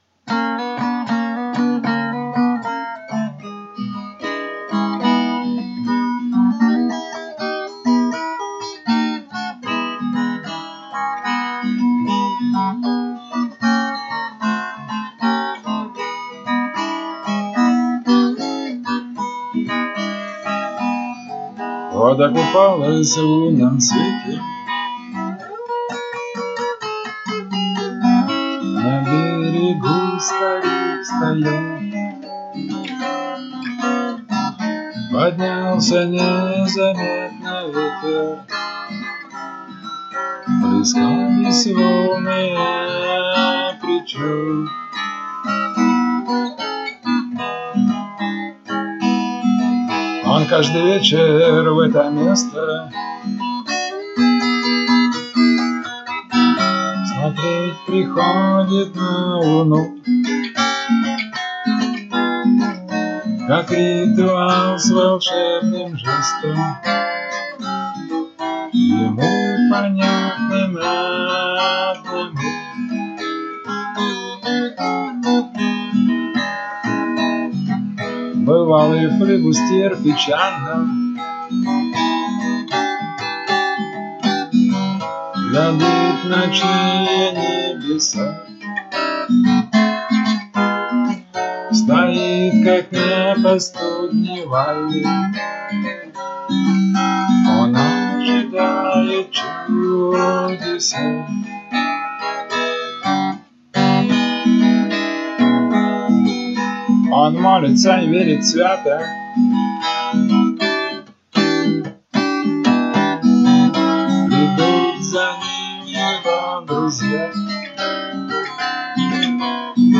Пиратский блюз.mp3